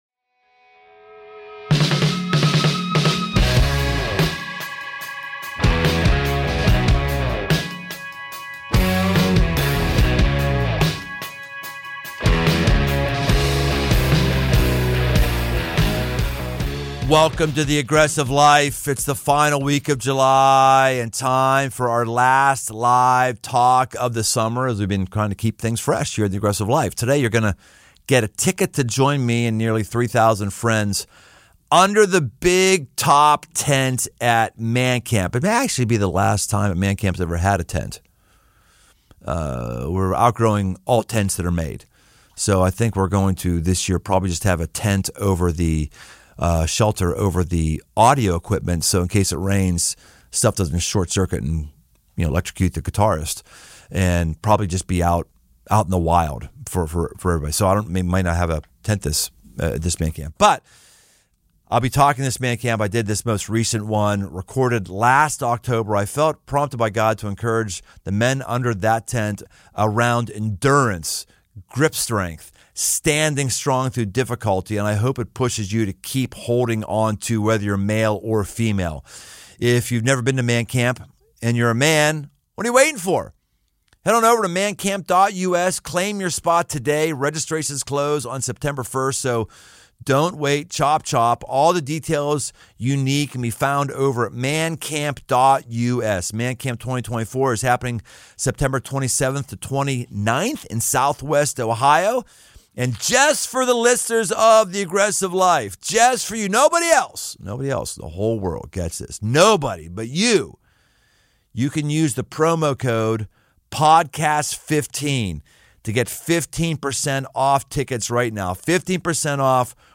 You're Stronger Than You Think You Are—Live at MAN CAMP
Recorded live in front of nearly 3,000 guys at MAN CAMP 2023, this final live talk of the summer combines grit and encouragement we all need.